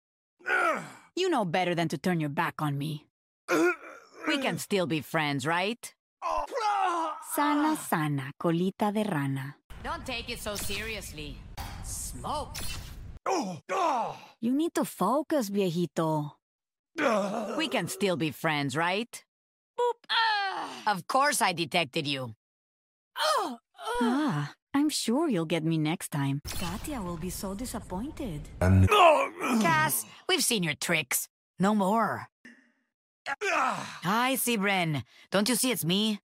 Sombra Elimination Voice Lines…